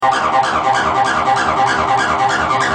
Sons et loops gratuits de TB303 Roland Bassline
Basse tb303 - 49